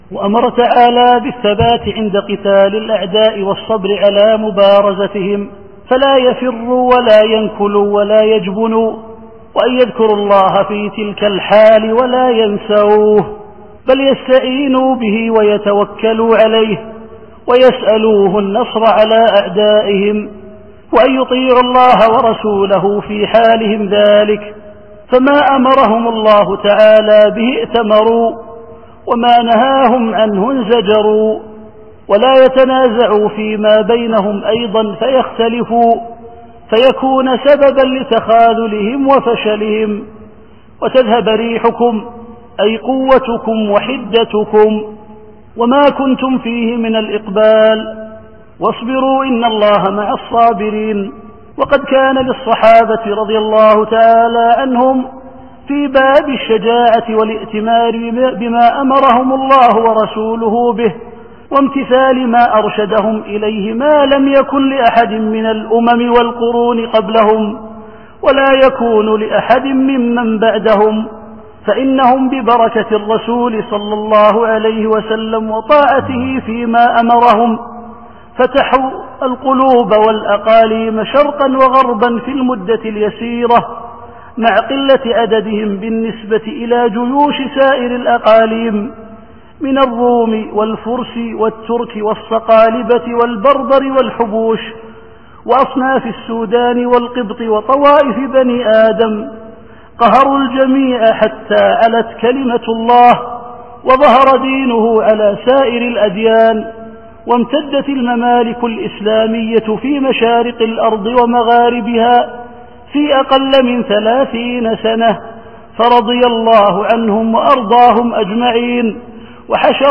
التفسير الصوتي [الأنفال / 46]